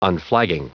Prononciation du mot unflagging en anglais (fichier audio)
Prononciation du mot : unflagging